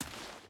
Footsteps / Dirt / Dirt Walk 5.ogg
Dirt Walk 5.ogg